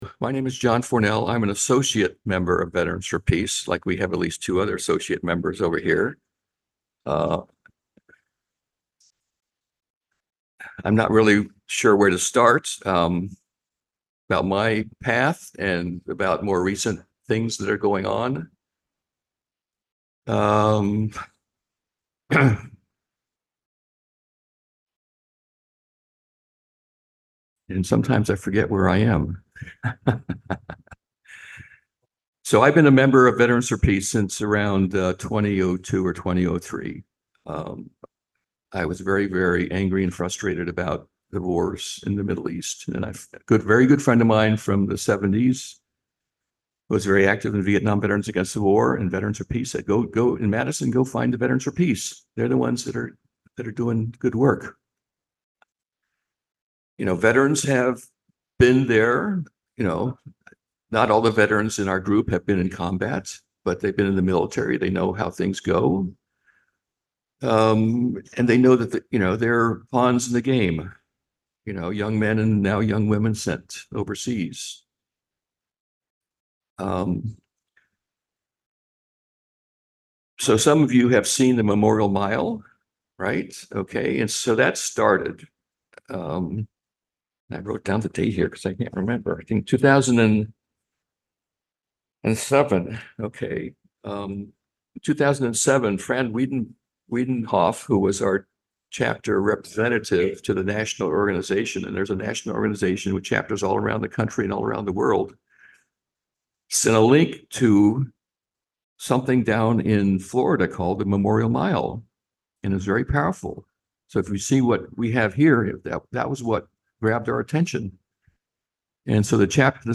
Waging Peace – James Reeb UU Congregation